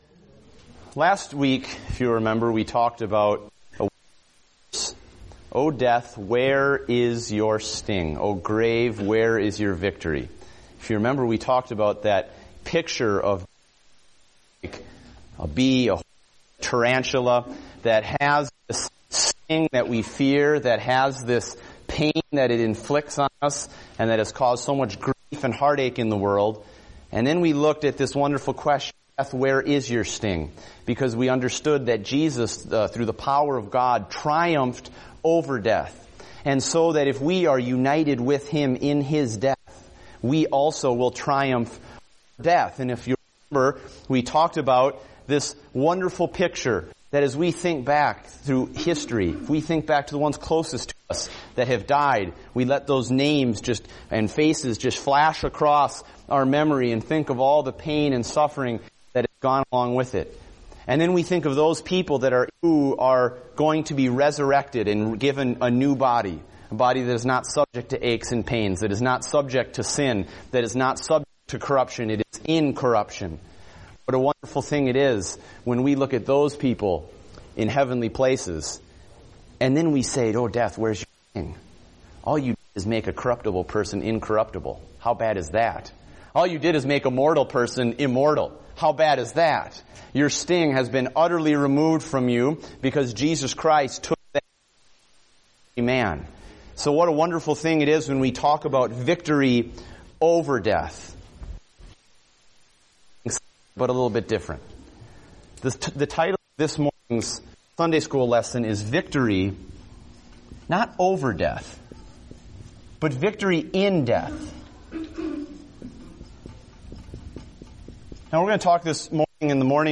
Date: October 26, 2014 (Adult Sunday School)